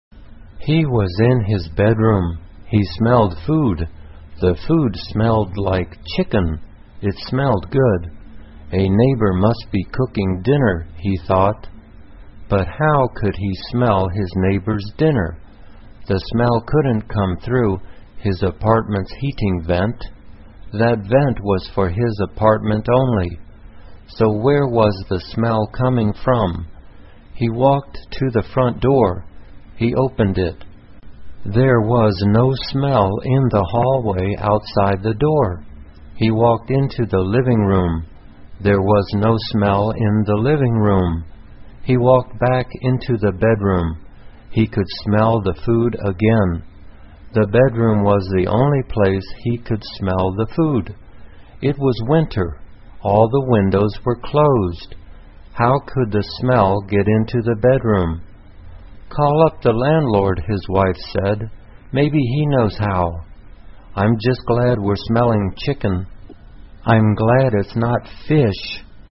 慢速英语短文听力 美味晚餐 听力文件下载—在线英语听力室